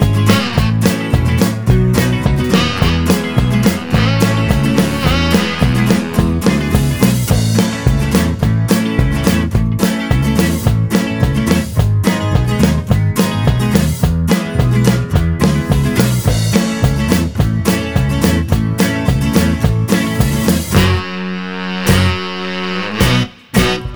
No Backing Vocals Rock 'n' Roll 2:49 Buy £1.50